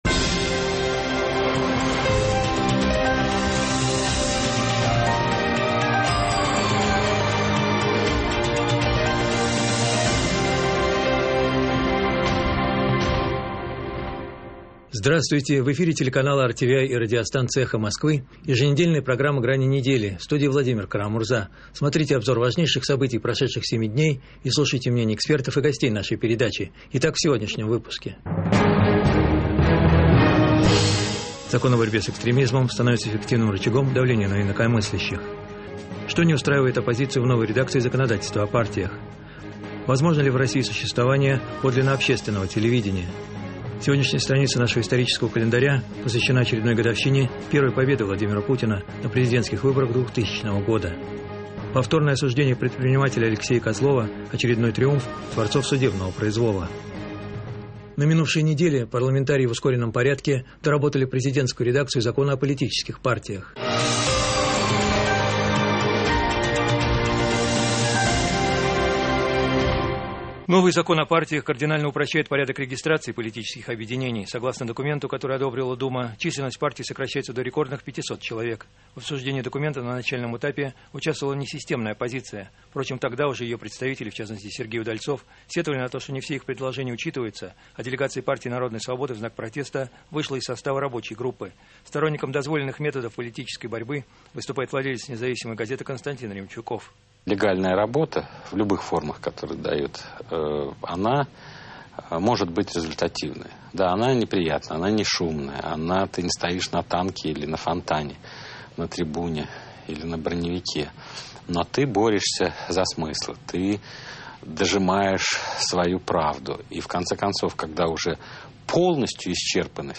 В. КАРА-МУРЗА: Здравствуйте.